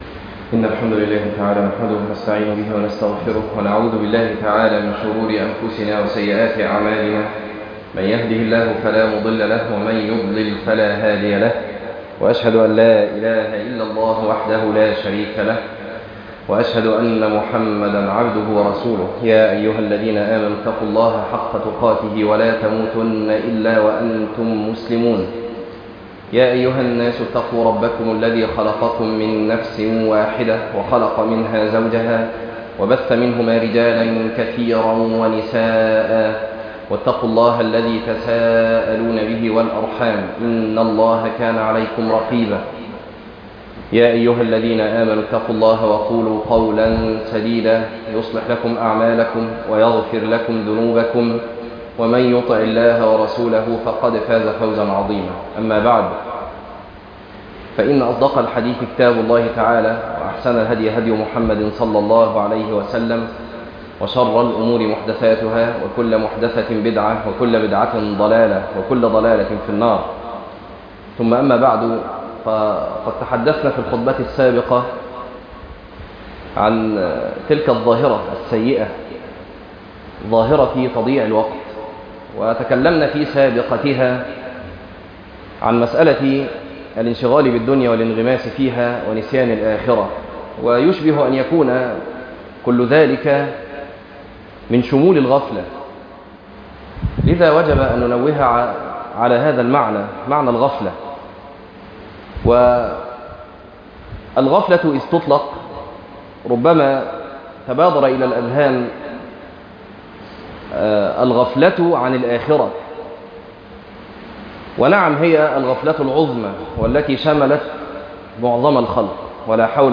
تفاصيل المادة عنوان المادة الغفلة واليقظة - خطبة تاريخ التحميل الأحد 12 ابريل 2026 مـ حجم المادة 10.94 ميجا بايت عدد الزيارات 14 زيارة عدد مرات الحفظ 9 مرة إستماع المادة حفظ المادة اضف تعليقك أرسل لصديق